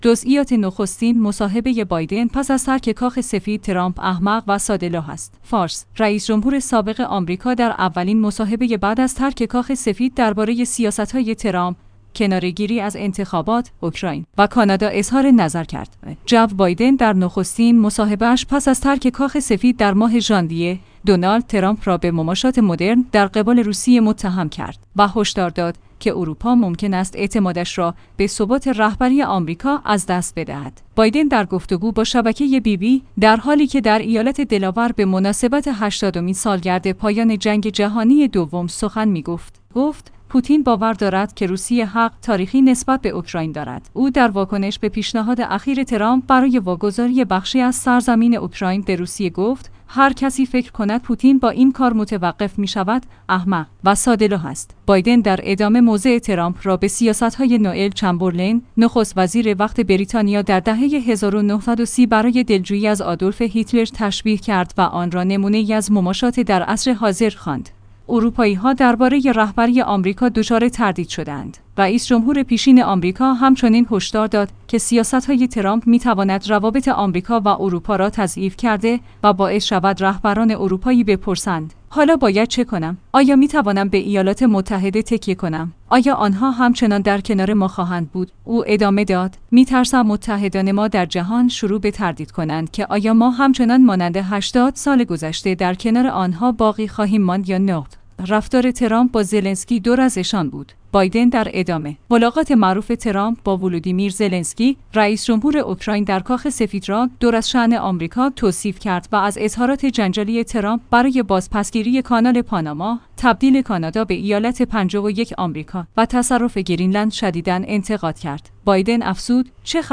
جزئیات نخستین مصاحبه بایدن پس از ترک کاخ سفید؛ ترامپ احمق و ساده‌لوح است